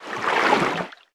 Sfx_creature_titanholefish_swim_05.ogg